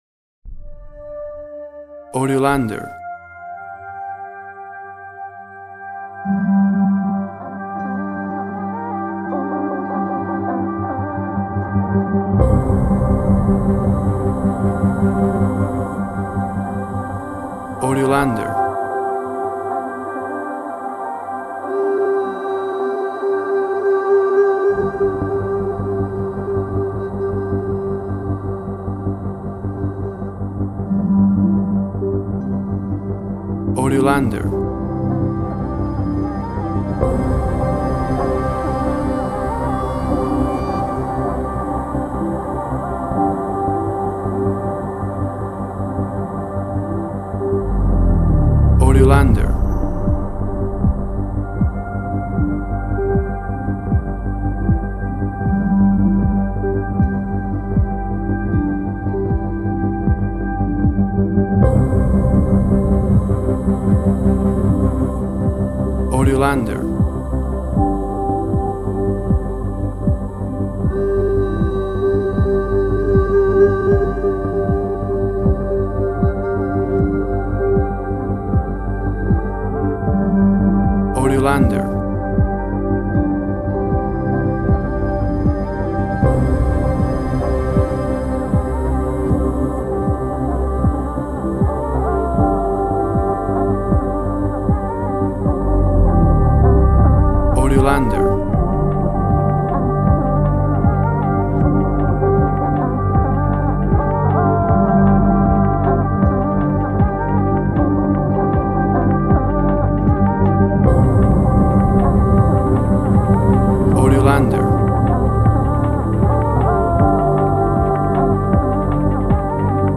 WAV Sample Rate 16-Bit Stereo, 44.1 kHz
Tempo (BPM) 78